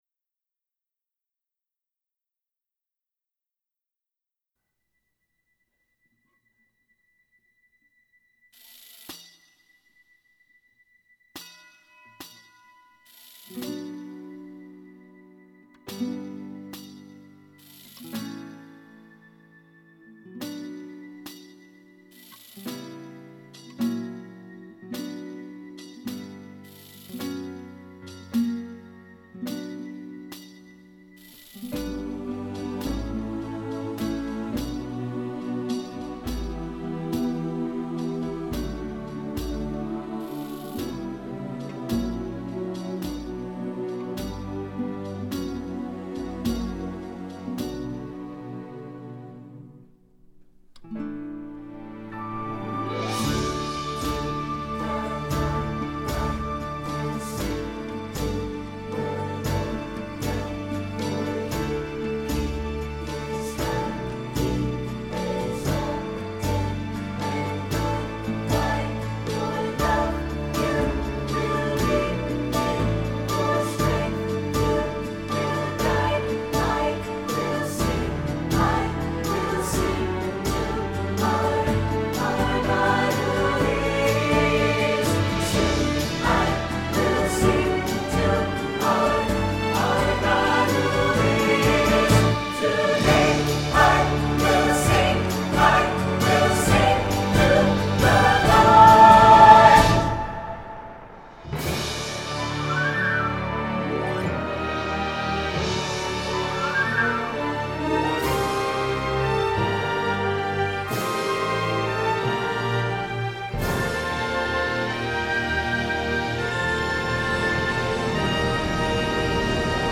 These are rough mixes after the L.A. brass and vocals.
Missing actor solos and voiceovers.